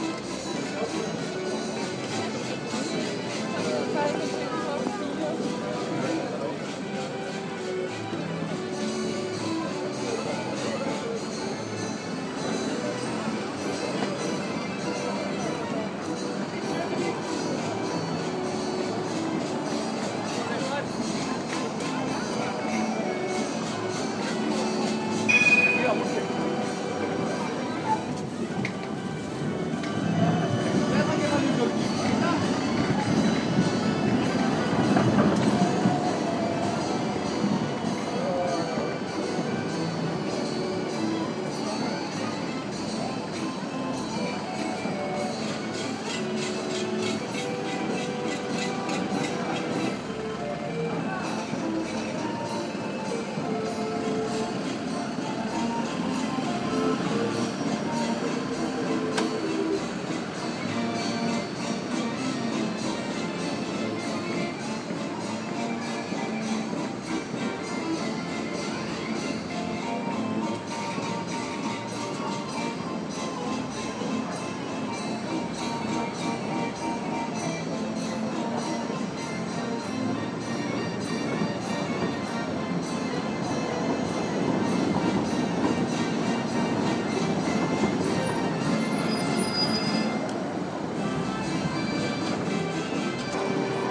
hurdy gurdy and trams on Dam square
Christmas market in Central Amsterdam, with the hurdy gurdy operators shaking cans for coins